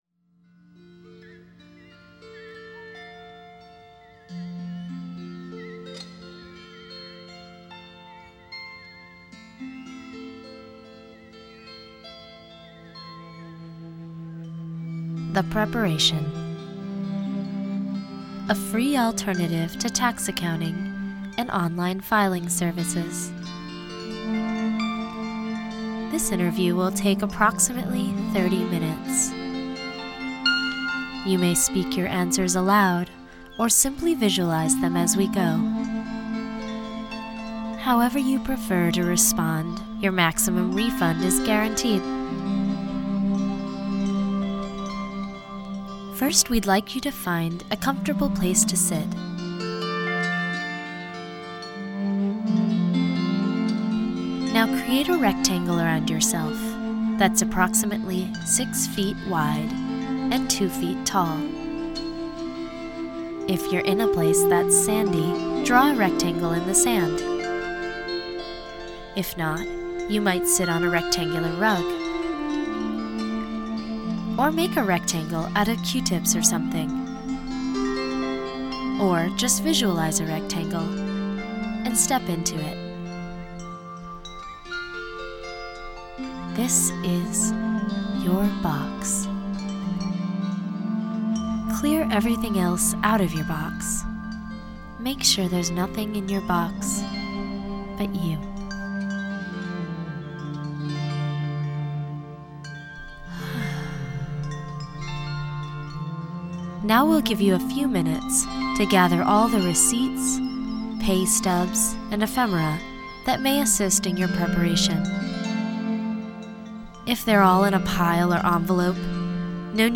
The Lite is a meditation series optimized for the adult contemporary lifestyle.